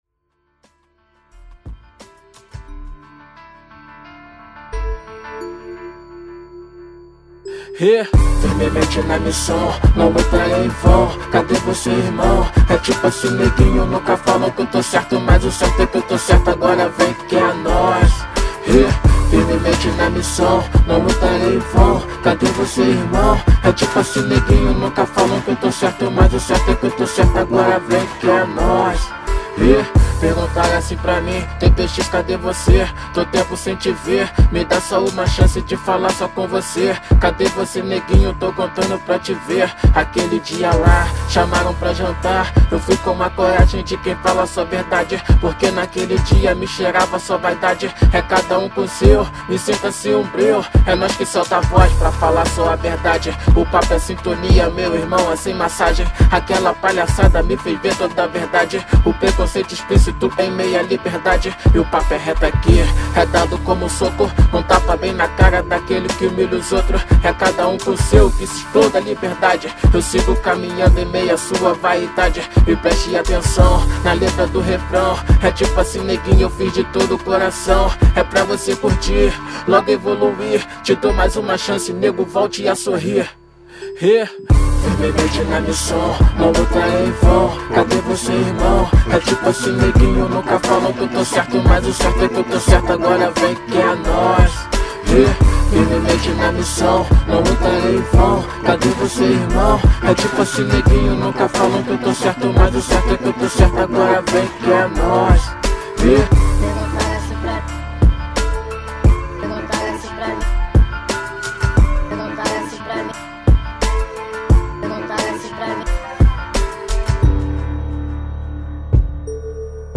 Rap Nacional